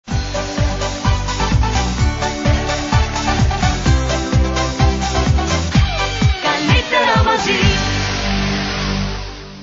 standard station ident